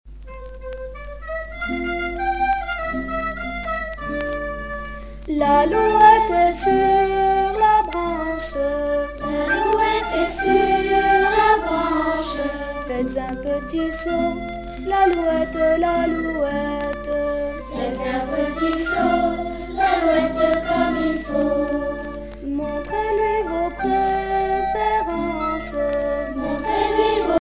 Rondes enfantines à baisers ou mariages
par des enfants de France
Pièce musicale éditée